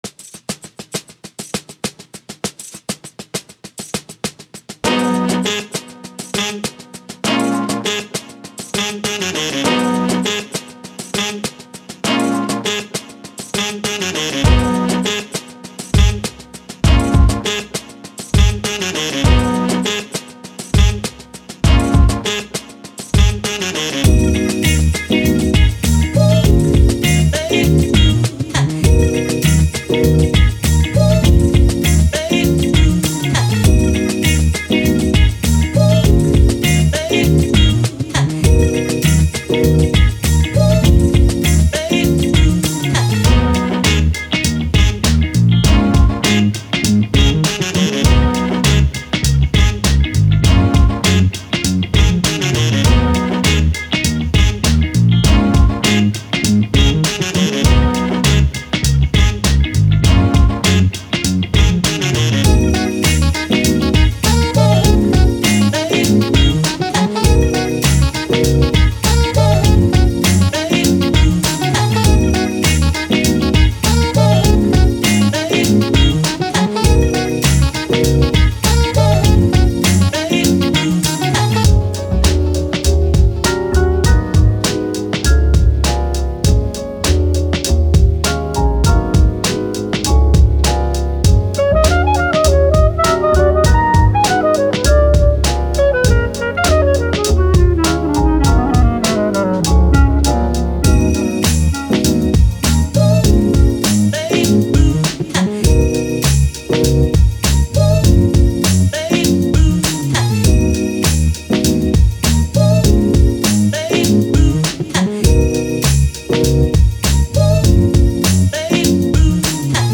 Hip Hop, Soul, Jazz, Fun, Playful, Positive